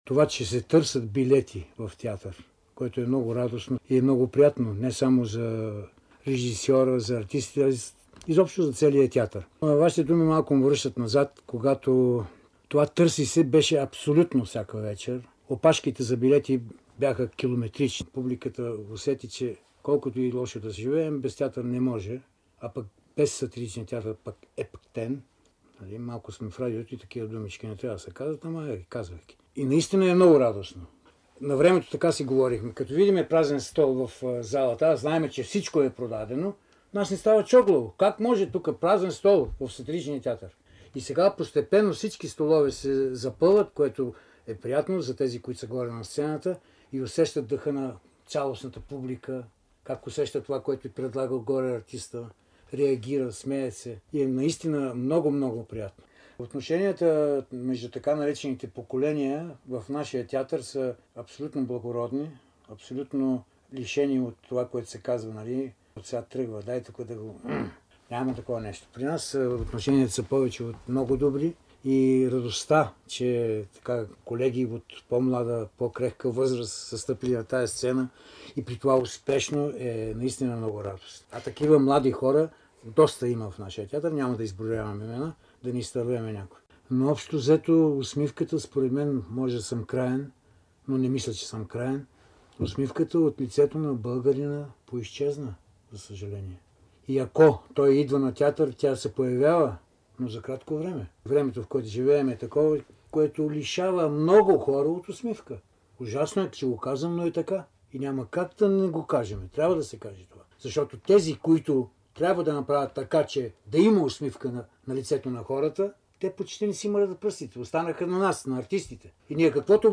Димитър Манчев споделя за емоциите в Сатиричния театър и за тъгата по изчезналата, от лицата на българите, усмивка, запис 2004 година, Златен фонд на БНР: